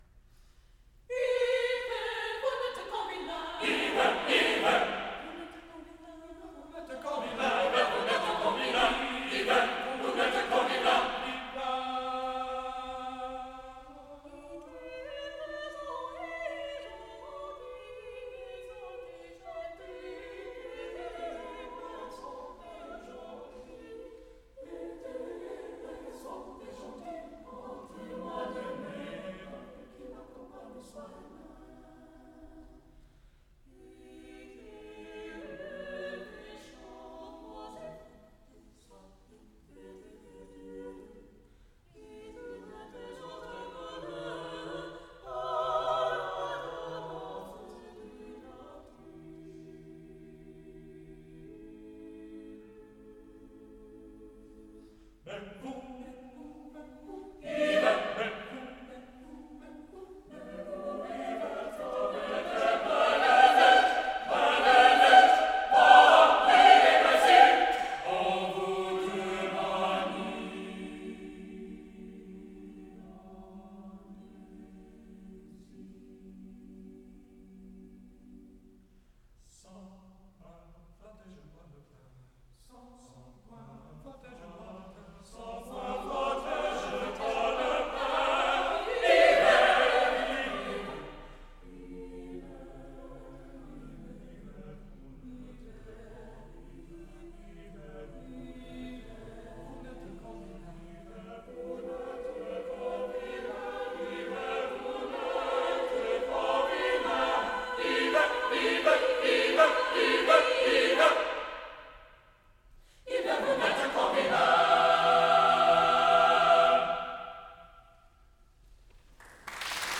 csucs - Music from the CSU Chamber Singers while I was a member
main csucs / NCCO Inaugural Conference Closing Concer / 12 Trois Chansons - III.